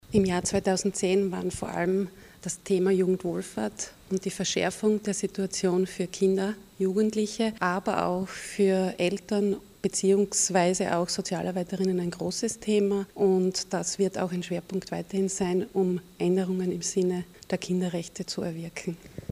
Kinder- und Jugendanwältin Brigitte Pörsch: